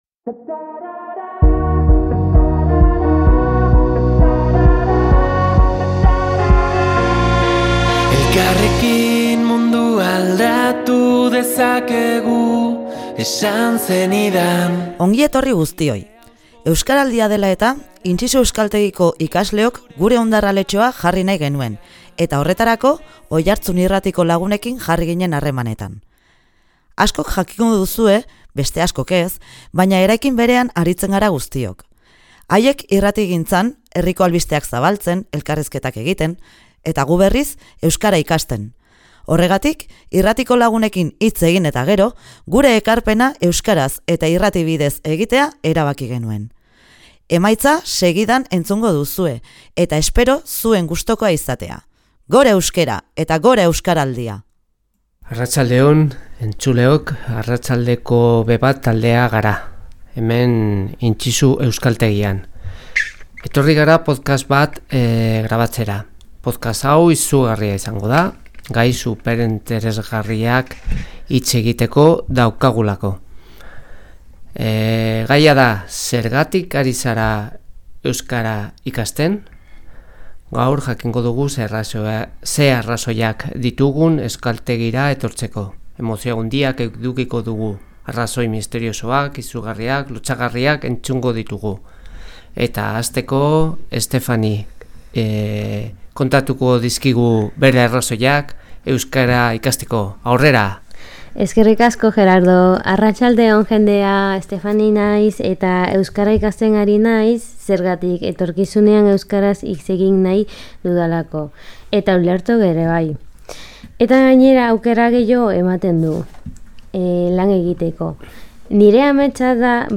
Intxixu AEK-ko ikasleekin Irrati tailerra egin dugu Oiartzun Irratian eta EUSKARALDIAREN harira podcasta osatu dute. A1 mailatik hasi eta C1 mailara arteko ikasleek hartu dute parte irratsaio honetan.